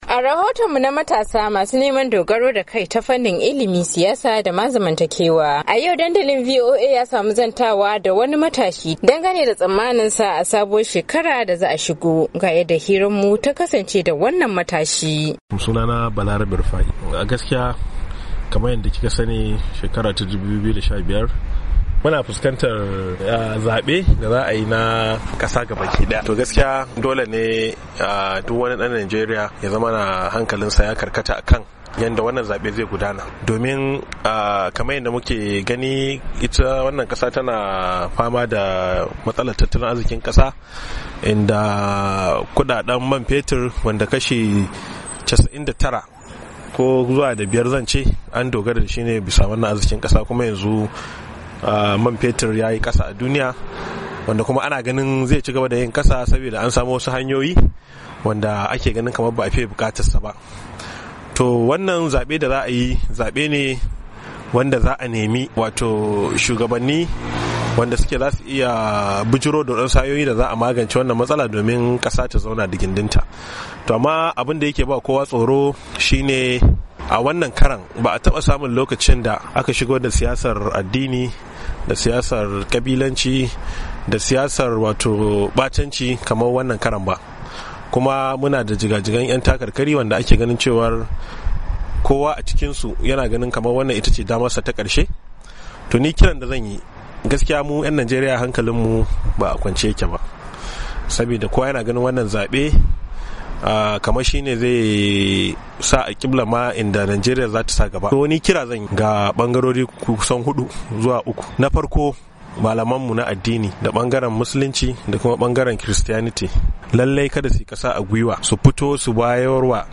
wani matashi ne da Dandalin Muryar Amurka ya zanta da shi